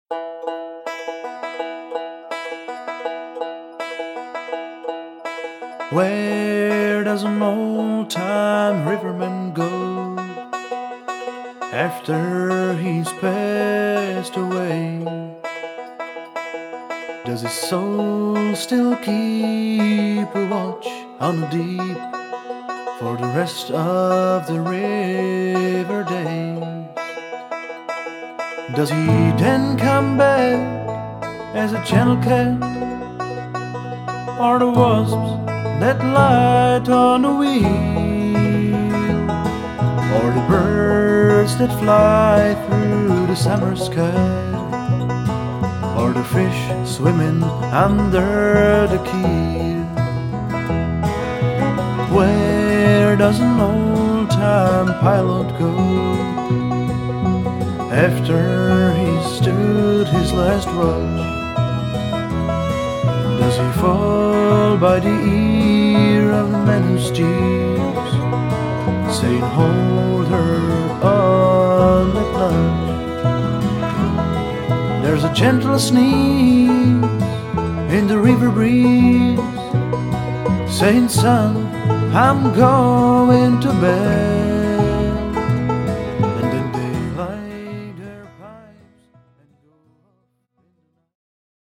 Recorded in February and March 2008 in Olomouc
banjo, guitar, harmony vocals
guitar, fiddle, lead nad harmony vocals
upright bass, lead and harmony vocals